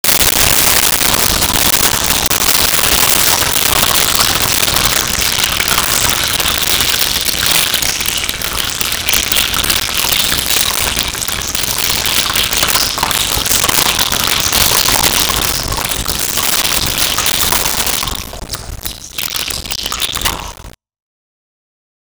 Dripping Cave
Dripping Cave.wav